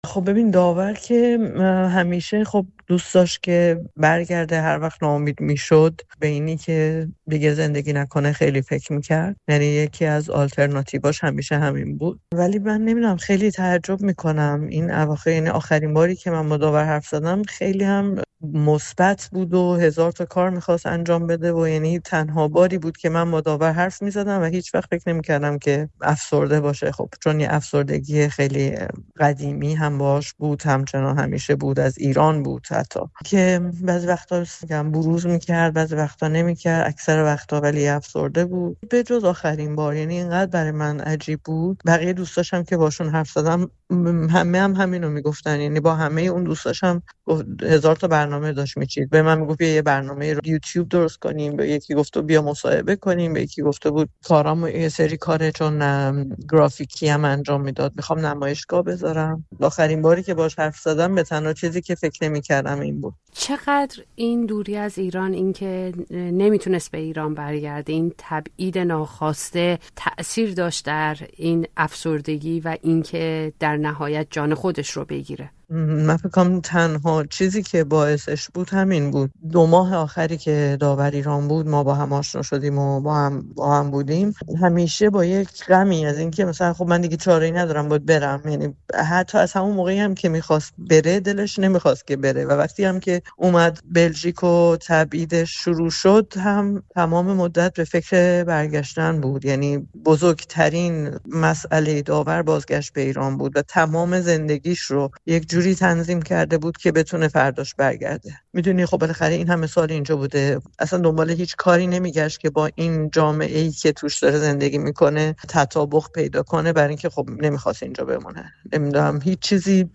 درباره او گفتگو کرده است. طنزنویس سرشناسی که با نقدهای اجتماعی و سیاسی‌اش در قالب طنز، خیلی‌ها را خنداند چرا به زندگی خود پایان داد و چگونه زندگی کرد؟